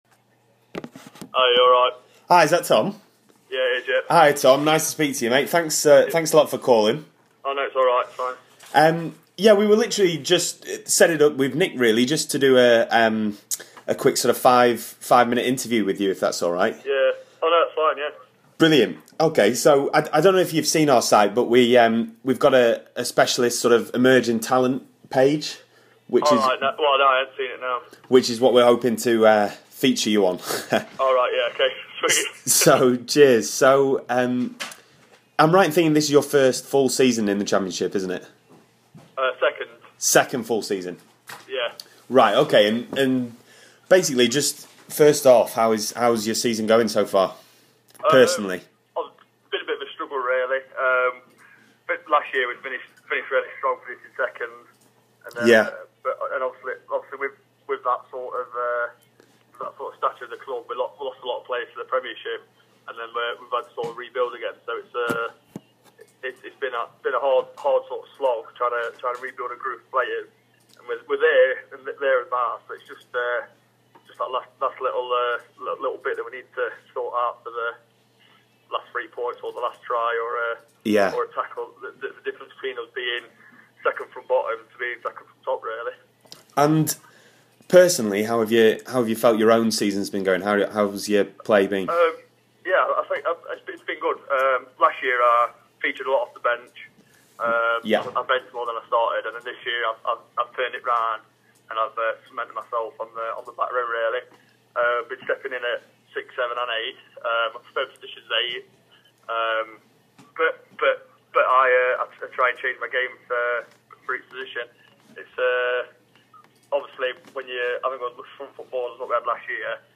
TRU Interview